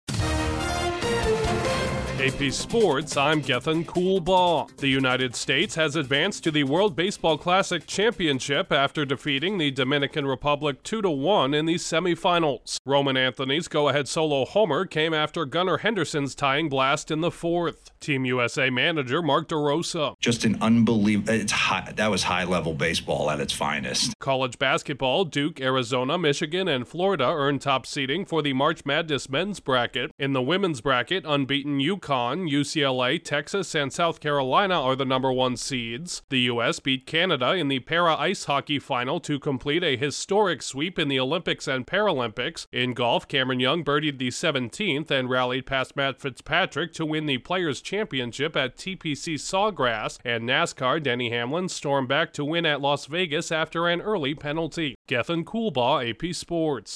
Team USA punches its ticket to the World Baseball Classic final, top seeds in the men’s and women’s NCAA Tournaments from Selection Sunday, the US wins Paralympic ice hockey gold, a golfer’s second PGA Tour victory at The Players Championship and a NASCAR star rallies at Las Vegas. Correspondent